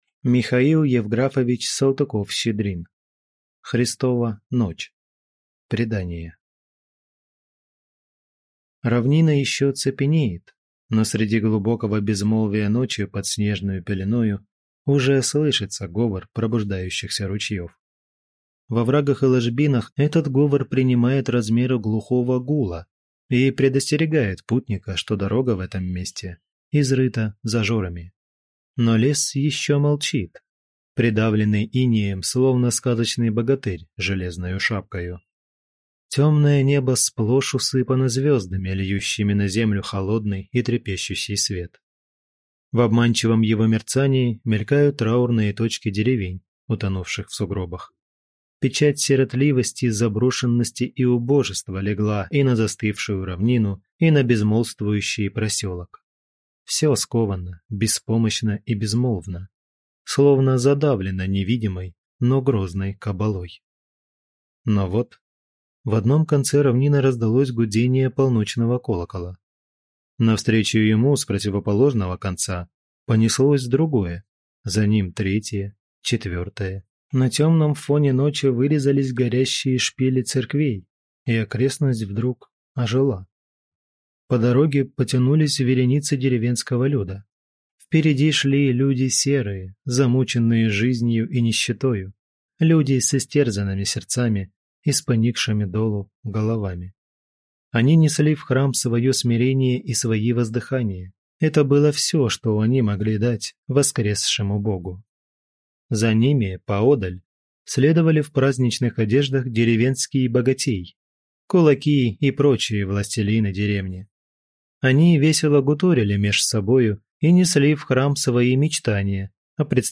Аудиокнига Христова ночь | Библиотека аудиокниг